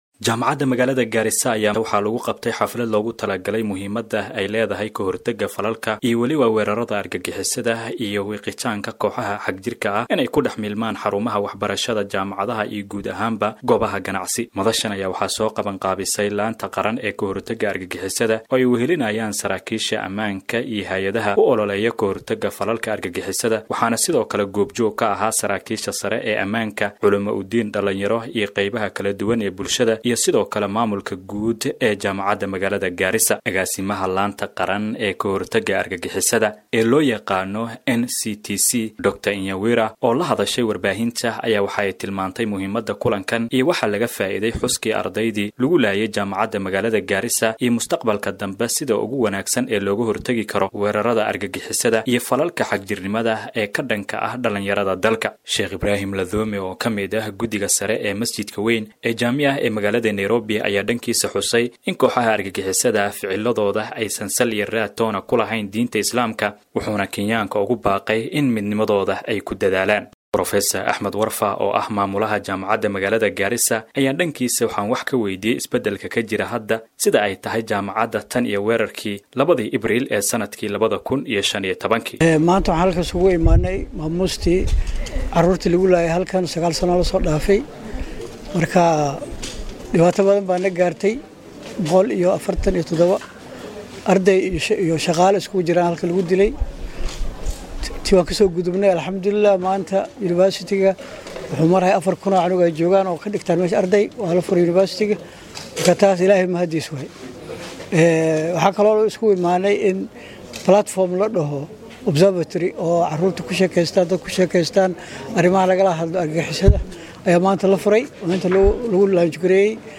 Wararka Kenya DHAGEYSO:Maxaa iska beddelay jaamacadda Garissa tan iyo weerarkii 2015-kii?